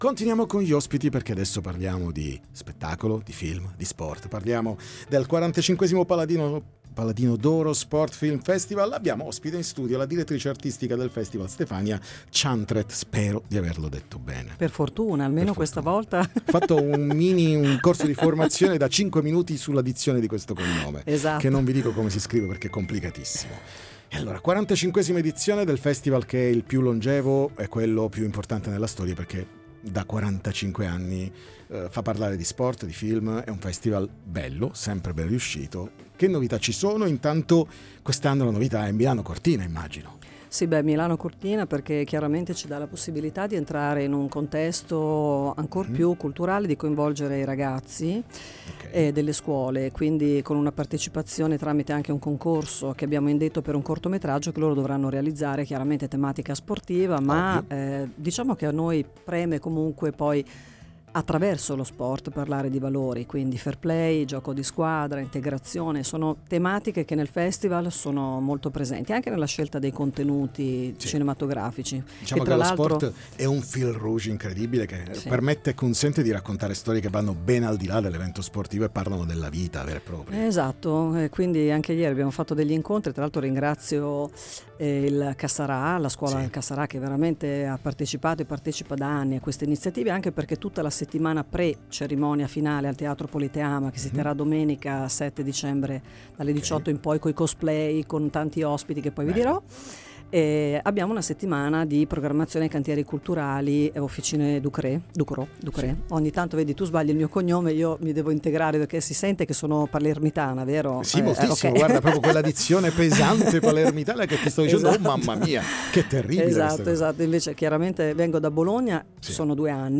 45° Paladino d’oro Sport Film Festival Interviste Time Magazine 05/12/2025 12:00:00 AM